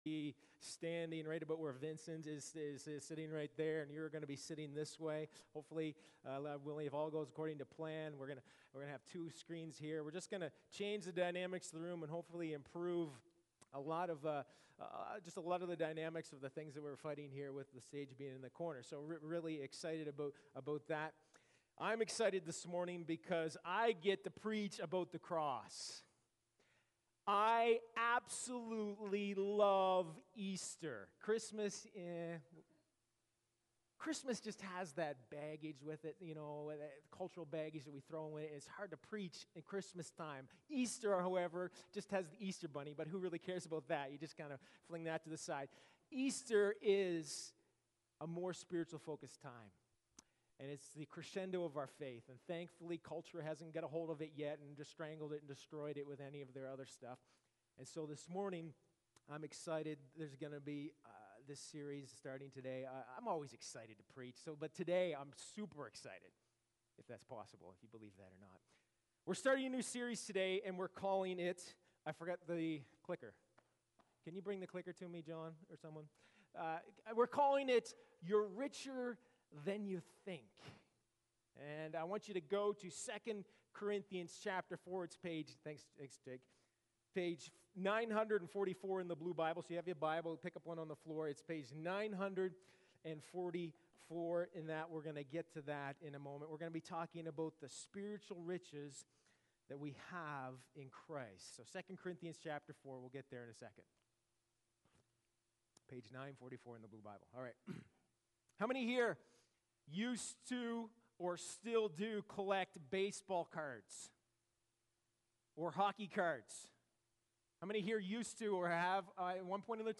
Sermons | Sturgeon Alliance Church